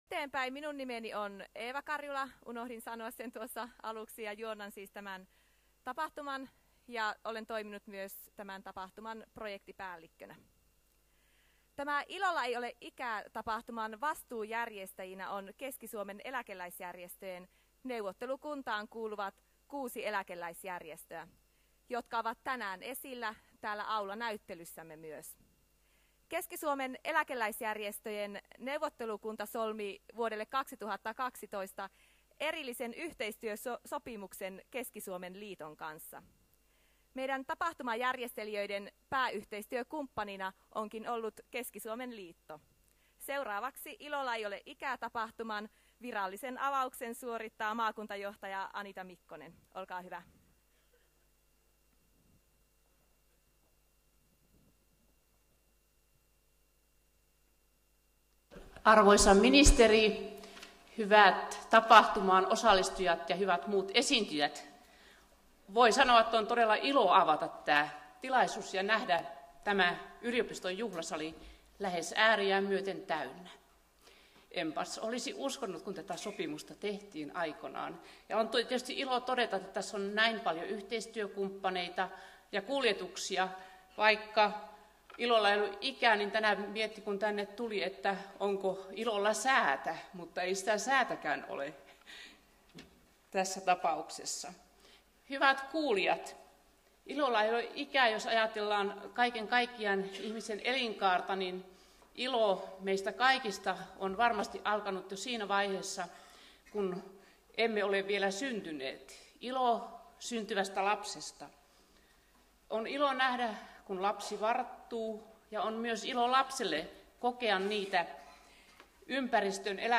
Maakuntajohtaja Anita Mikkonen, Keski-Suomen liitto